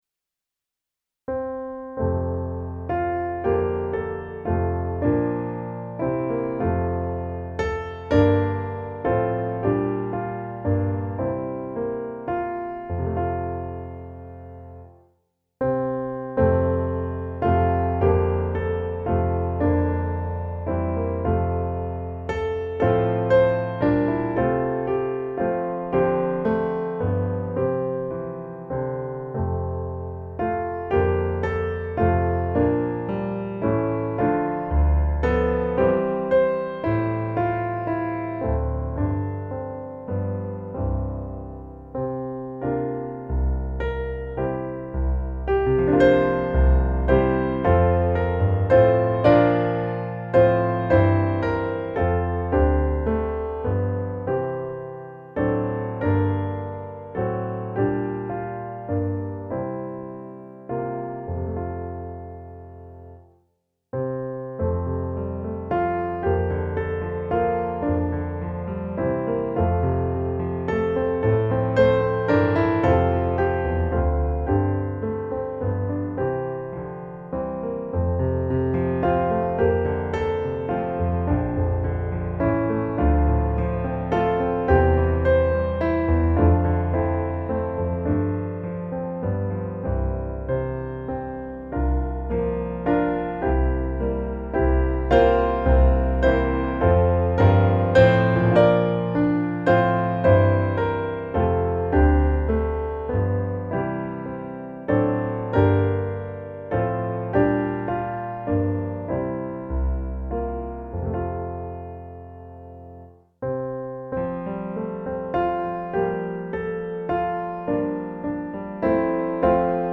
En vänlig grönskas rika dräkt - musikbakgrund
Gemensam sång
Musikbakgrund Psalm